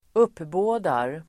Uttal: [²'up:bå:dar]